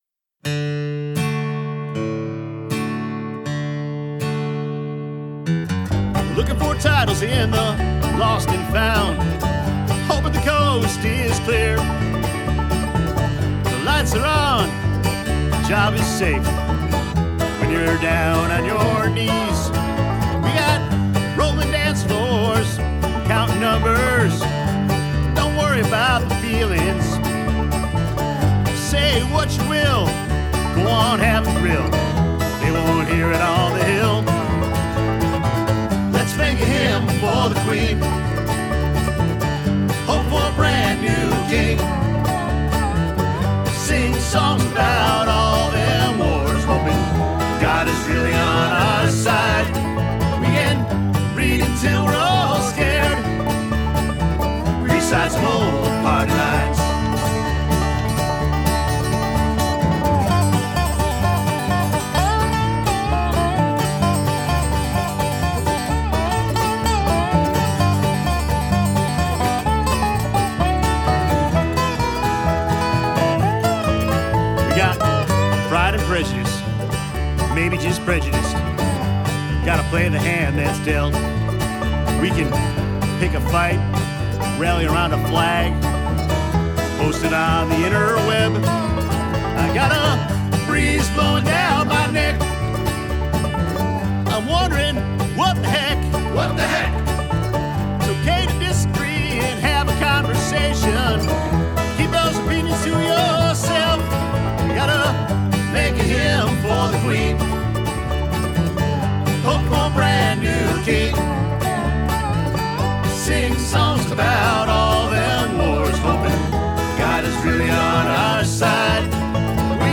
Genre: Acoustic.